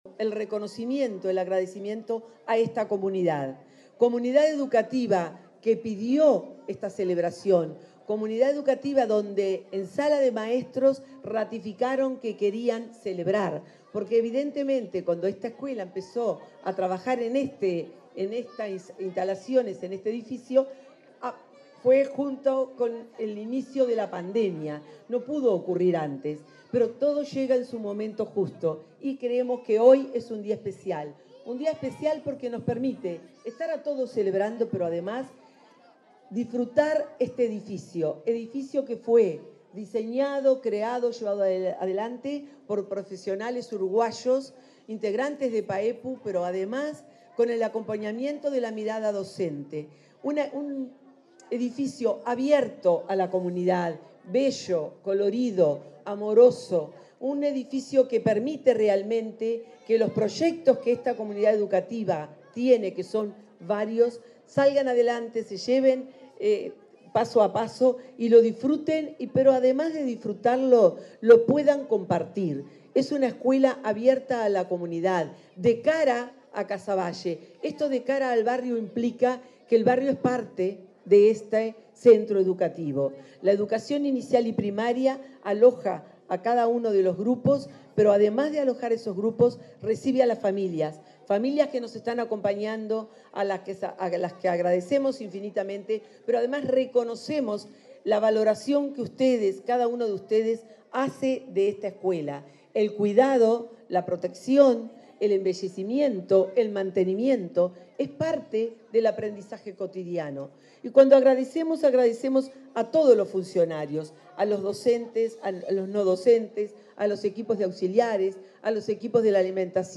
Palabras de autoridades en la inauguración de la escuela n.° 319